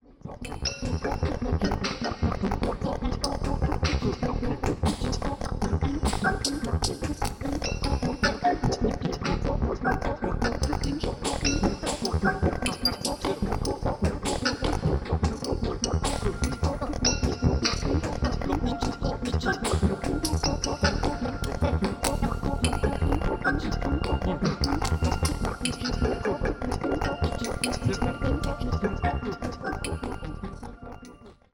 サンプル音源は全てステレオ(2ch)です。